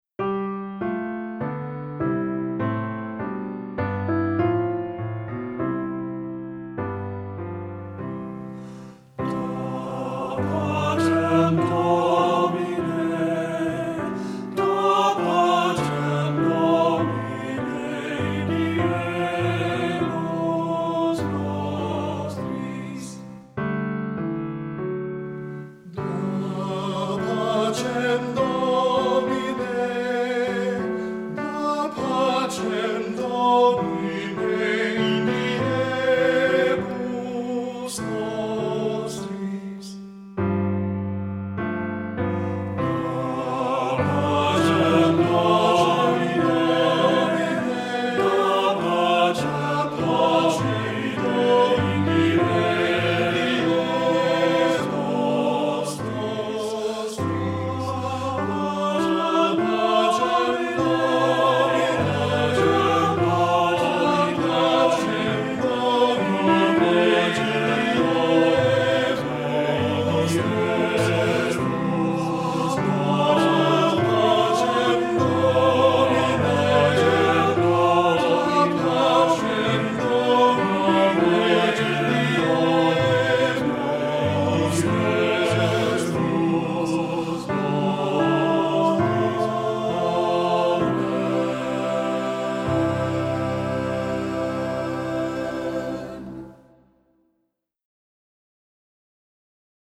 Voicing: TTB